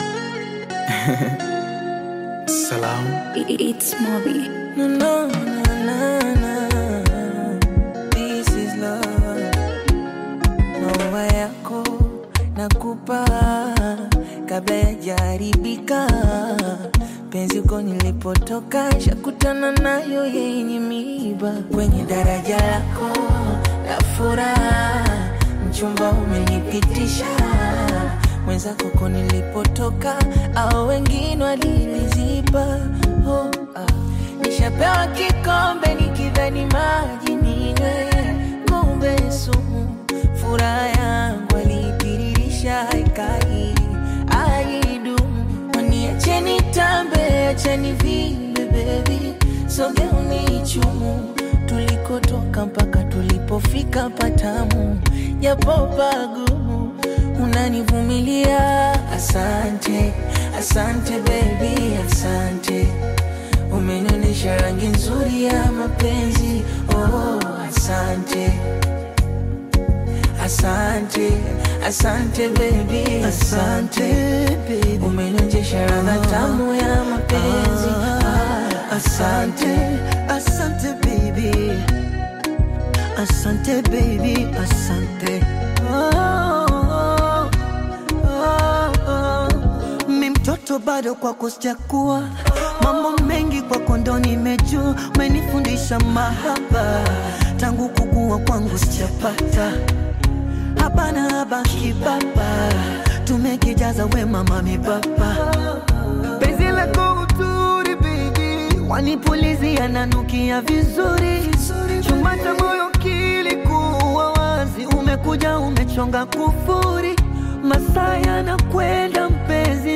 heartfelt Afro-Pop/Bongo Flava single
Genre: Bongo Flava